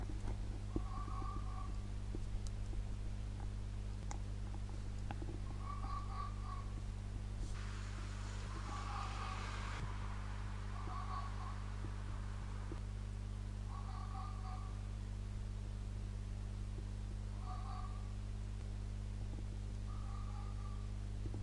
wild creatures » Fox Barking
描述：Fox barking in the distance (perhaps 100m away), around 1 AM in the Surrey Hills (UK).
标签： bark night fox cry countryside nature fieldrecording wildlife
声道立体声